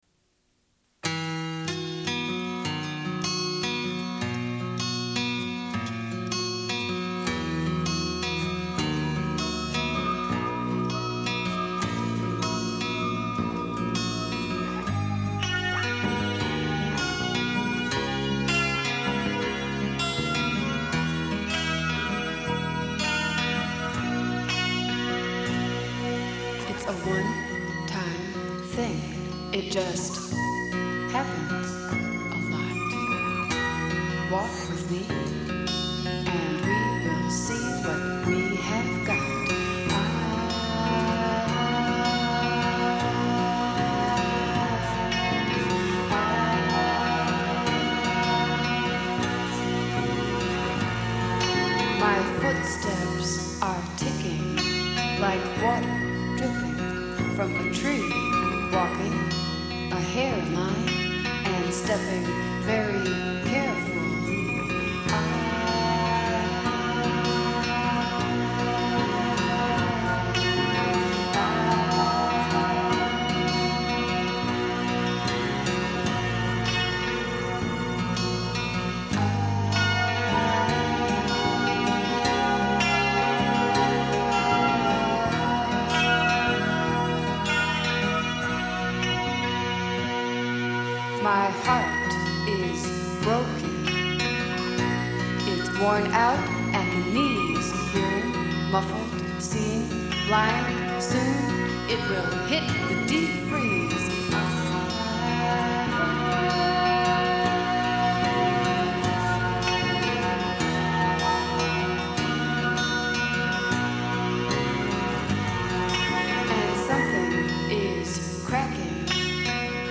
Une cassette audio, face A31:36
Sommaire de l'émission : diffusion d'un entretien de Germaine Acogny, danseuse sénégalaise au sujet de sa vie au Sénégal. Puis la suite autour du livre La religion par les femmes, cinquième émission.